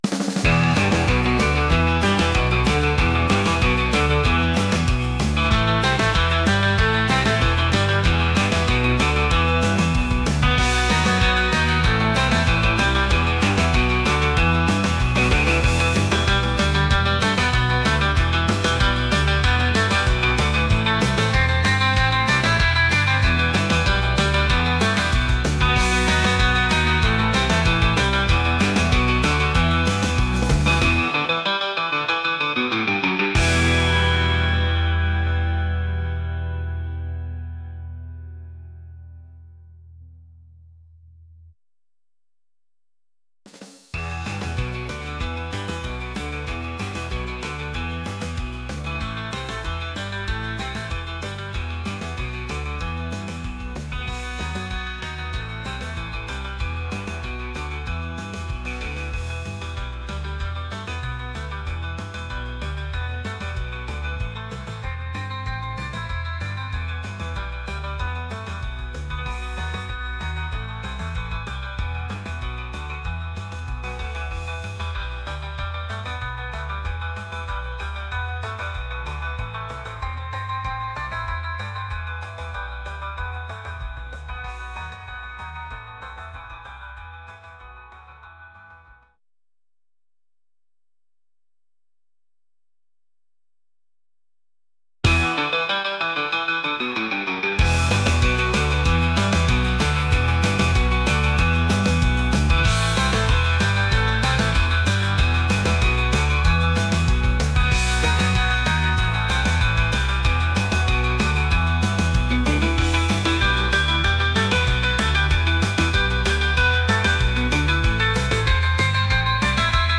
rock | energetic | groovy